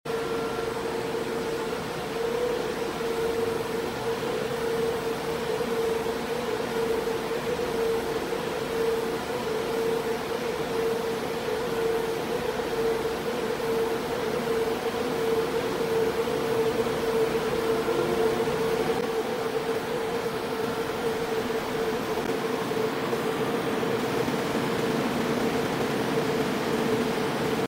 A CT Scanner Running Full Sound Effects Free Download